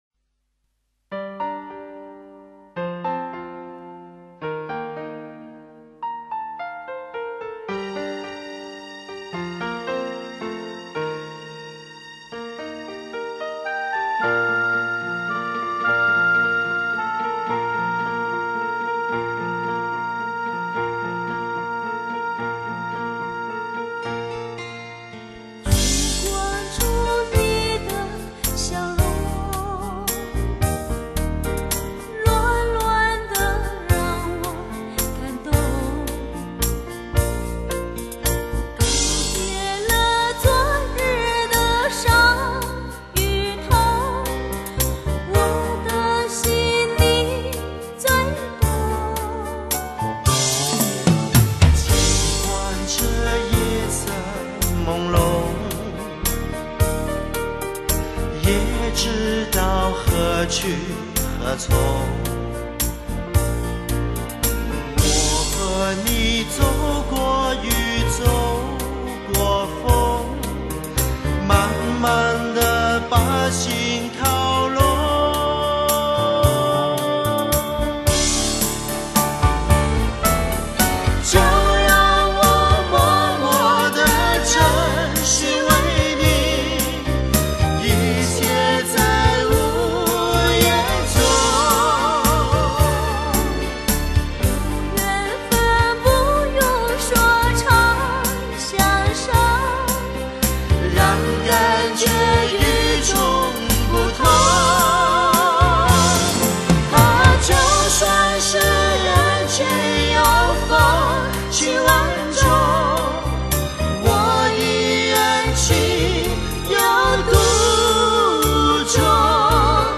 05 对唱情歌演绎 慢三